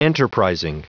Prononciation du mot enterprising en anglais (fichier audio)
Prononciation du mot : enterprising